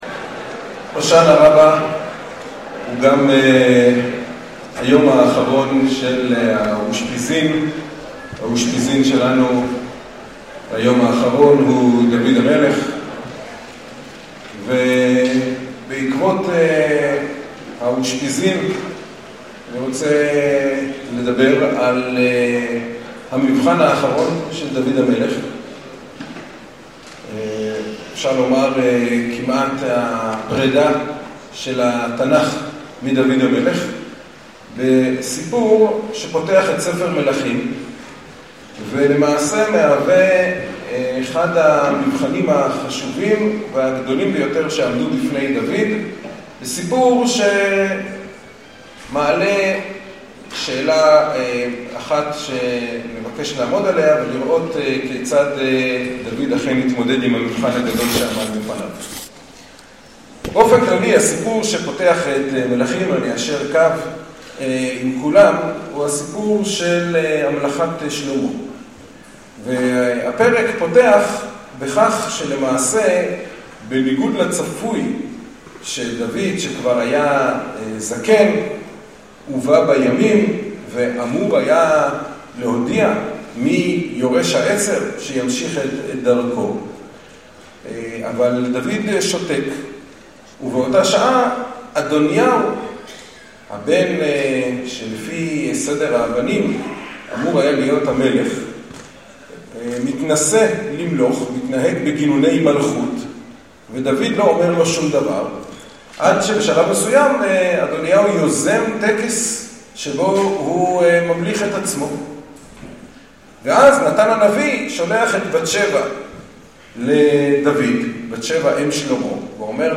השיעור הועבר בליל הושענא רבה תש"ף בהיכל שלמה, ירושלים